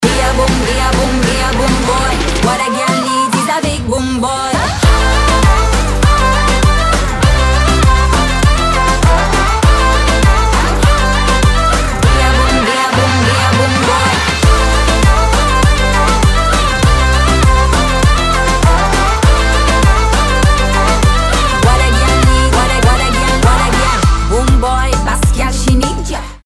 • Качество: 320, Stereo
зажигательные
dance
Moombahton
Заводной трек